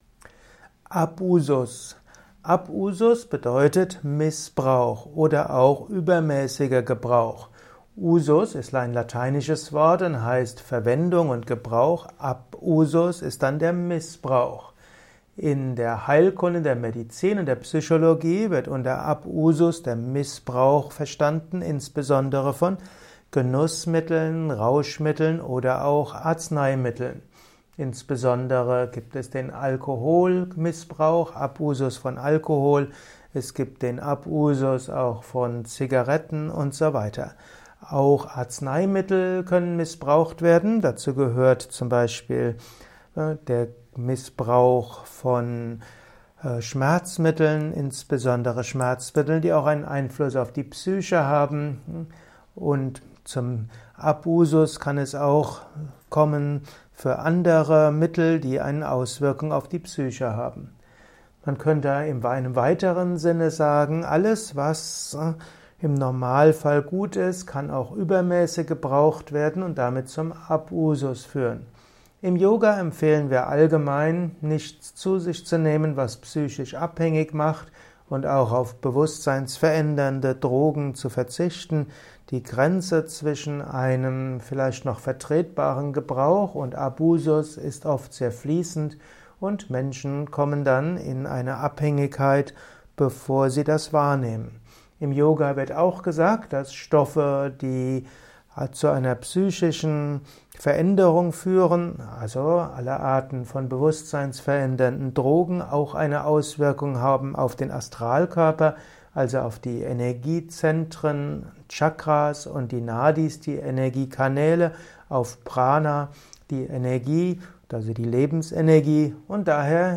Simple und komplexe Informationen zum Thema Abusus in diesem Kurzvortrag. Lausche einigen Ausführungen zum Thema Abusus aus dem Geist des ganzheitlichen Yoga.